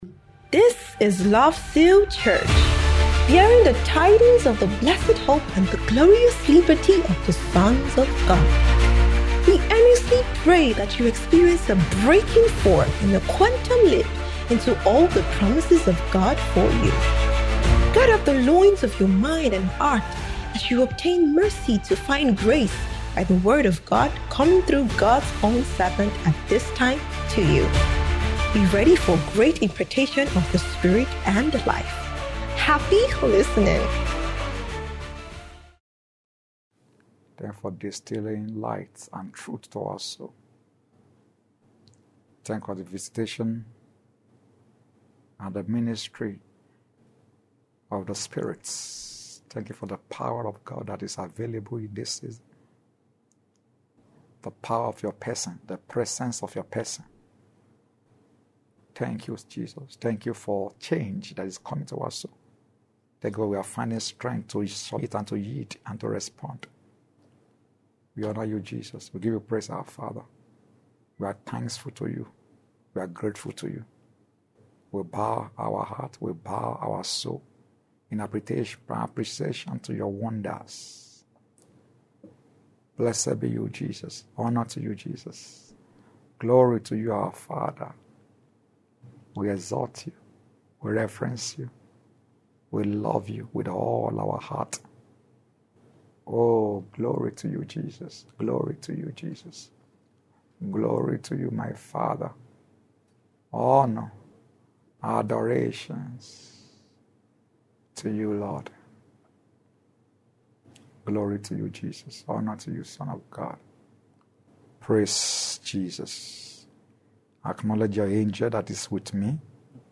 SOBT - SPECIAL MIDWEEK TEACHING SERIES